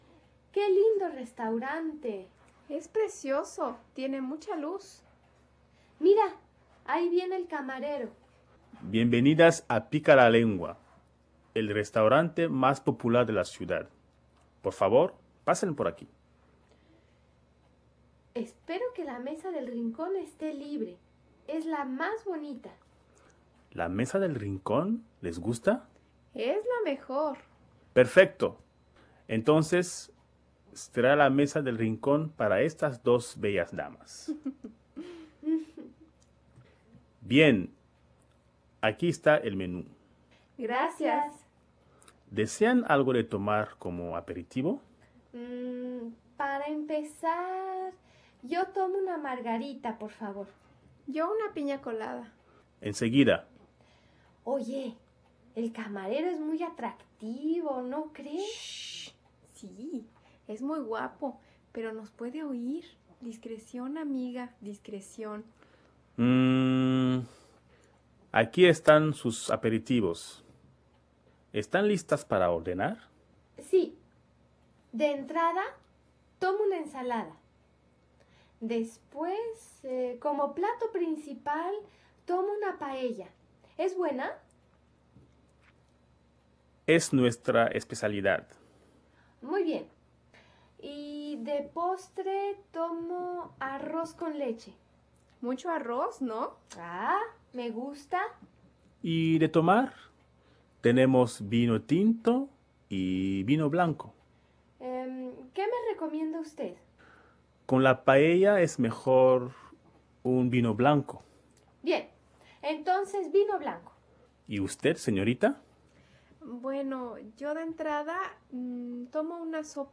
Escucha esta conversación entre dos amigas que van al restaurante y trata de completar el menu.